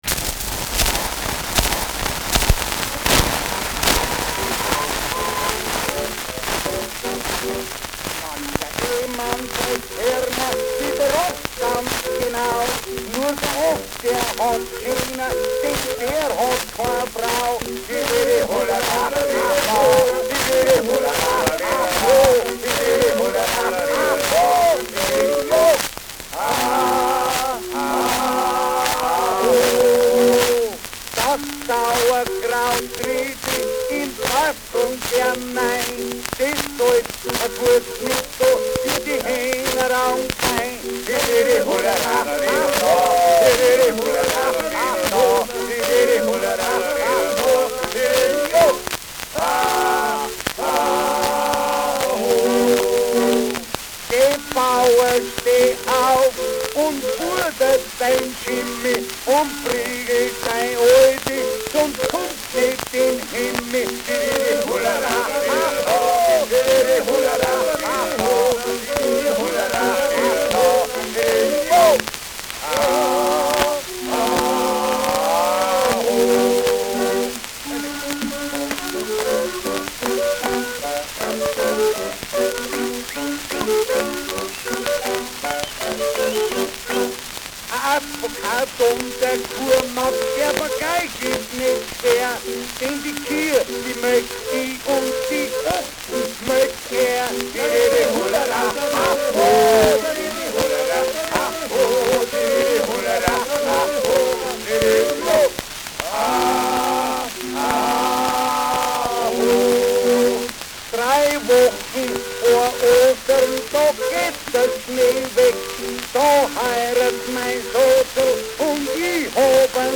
Schellackplatte
Stark abgespielt : Anfang stark verrauscht : Nadelgeräusch : Gelegentlich stärkeres Knacken
Adams Bauern-Trio, Nürnberg (Interpretation)
[Nürnberg] (Aufnahmeort)